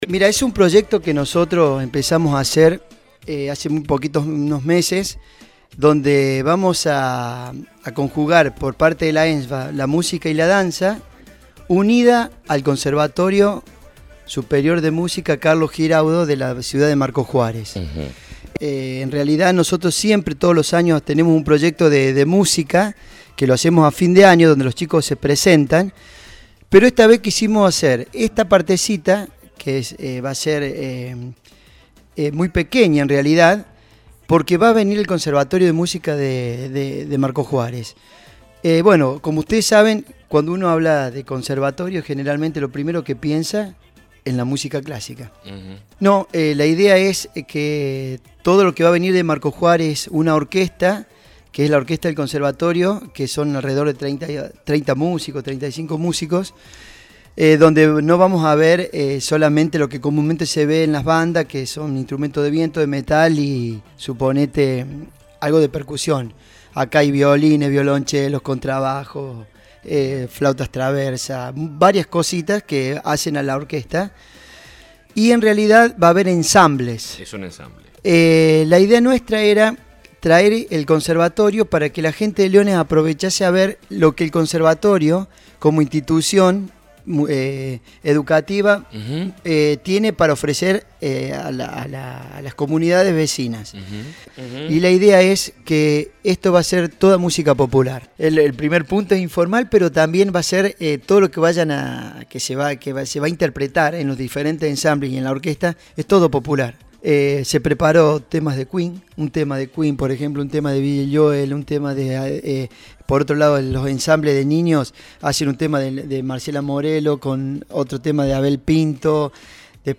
visitó los estudios de La Urbana y anticipó detalles: